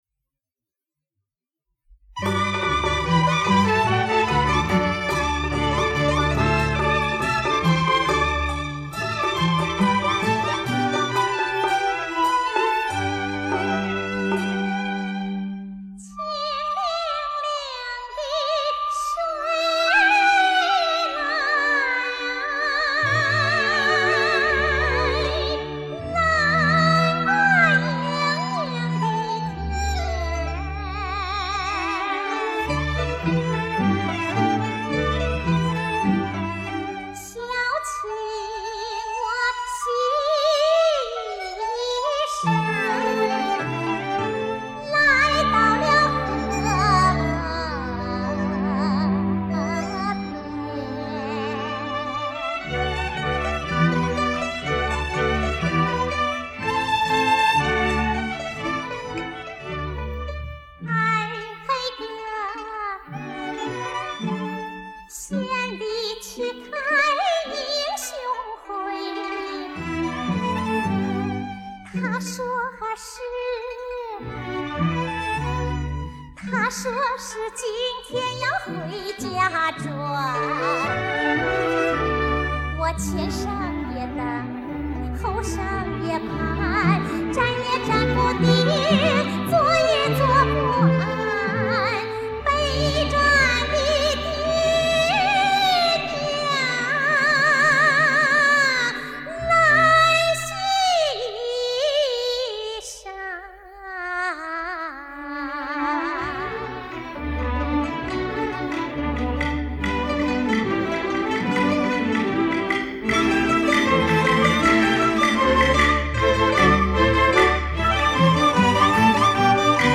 刻录盘抓轨